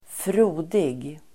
Ladda ner uttalet
Uttal: [²fr'o:dig]
frodig.mp3